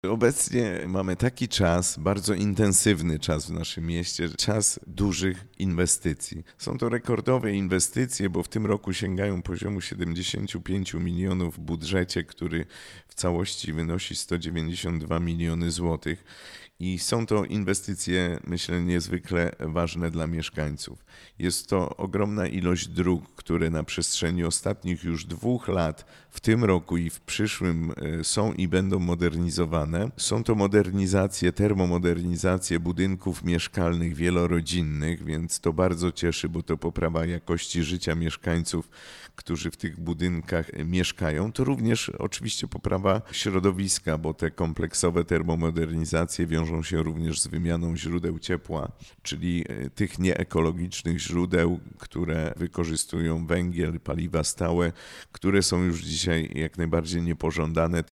W audycji „Poranny Gość”